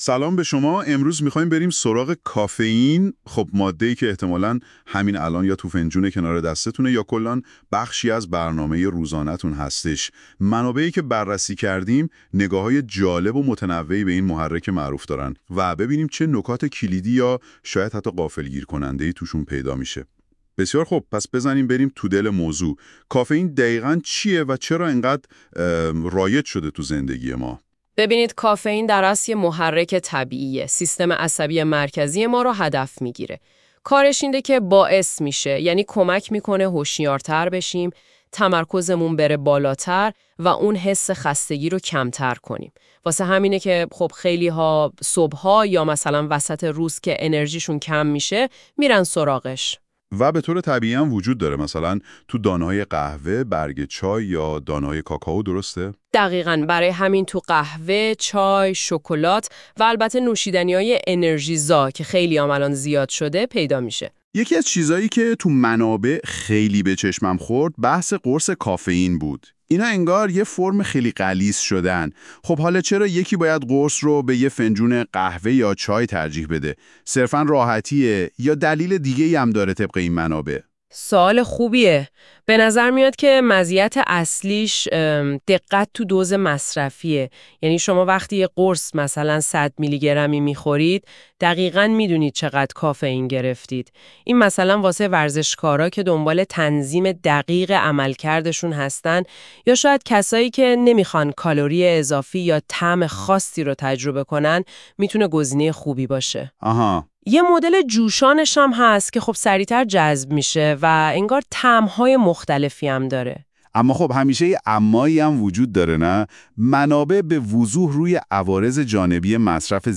گفتگو در مورد کافئین قرص کافئین چیست ؟